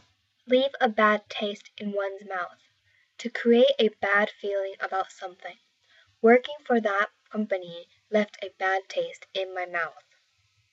このイディオムが使われるのは、実際に何かを食べて、後味が悪いという意味と、何かの経験が嫌な印象や記憶を残すという意味の２つの場合があります。 英語ネイティブによる発音は下記のリンクをクリックしてください。